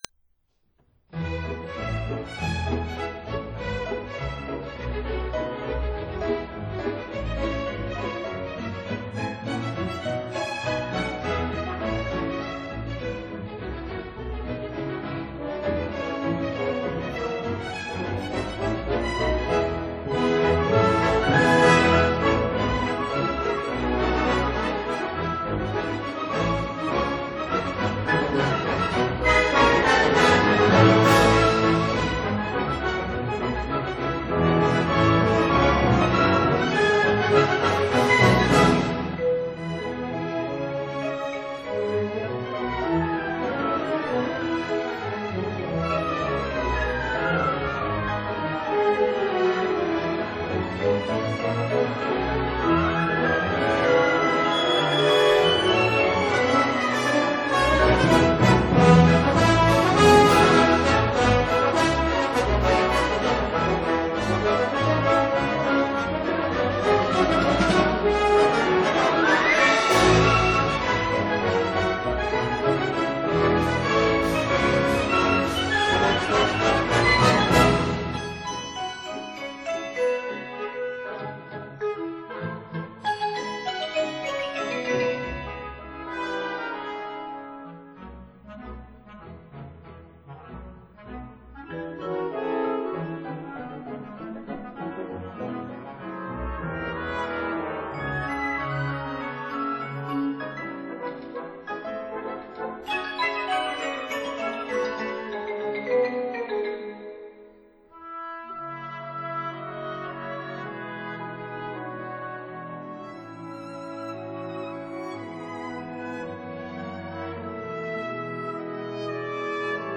(08-13) Lincolnshire Posy for military band